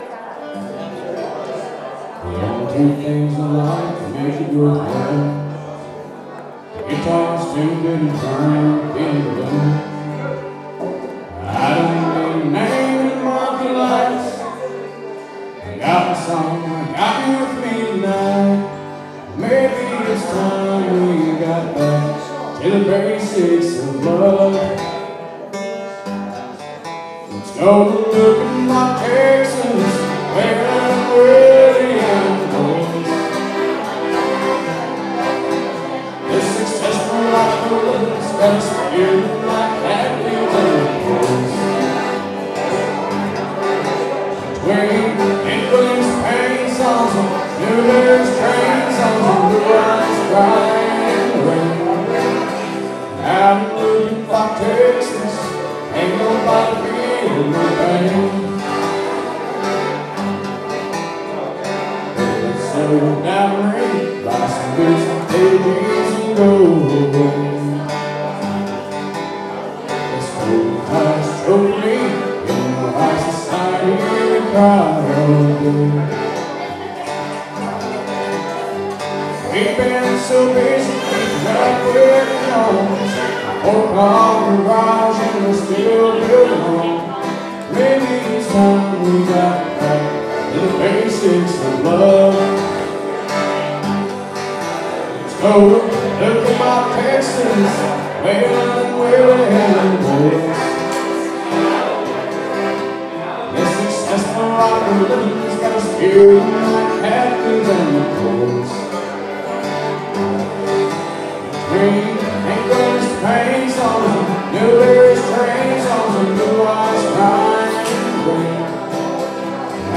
4 pm: York Hall potluck and jam, York
It’s hard to get good sound when recording in community halls where there’s lots of room reverb from the floor and walls but all the same
vocals, guitar
accordion
fiddle